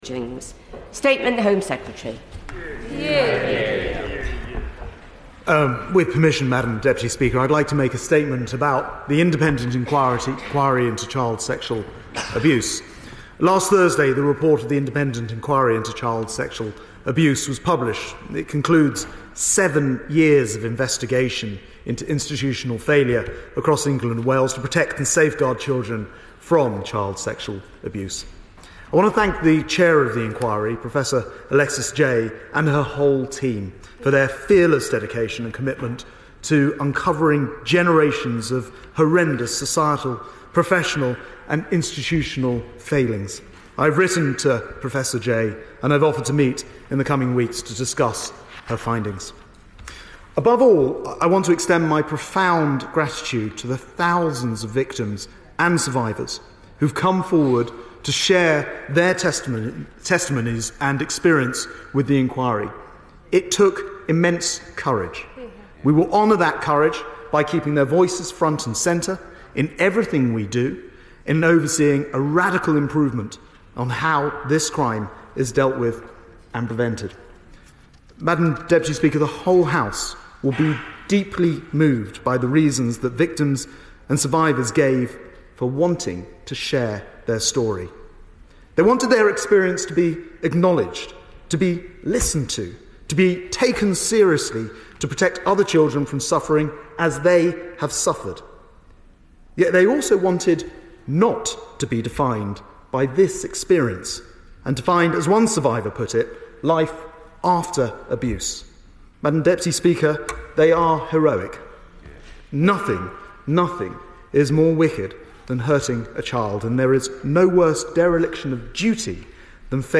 24.10.22 - Statement to HoC by Home Secretary - IICSA. These are the full proceedings.
Subscribe Next Lord Sharpe repeating a statement by the Home Sec (du jour) made earlier in the HoC. The debate that follows starts at 6.56. and contains some interesting input about mandatory reporting.